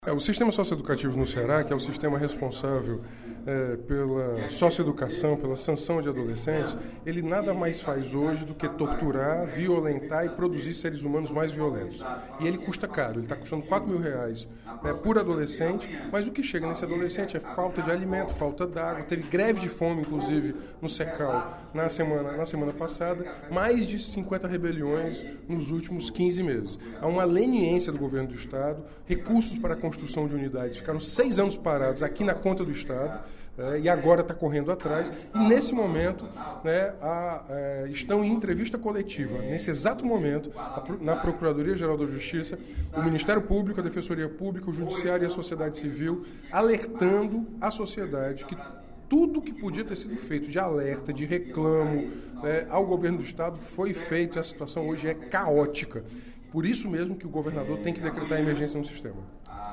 O deputado Renato Roseno (Psol) pediu ao Governo do Estado, durante pronunciamento no primeiro expediente da sessão plenária desta quarta-feira (21/10), que decrete estado de emergência para as unidades socioeducativas do Ceará.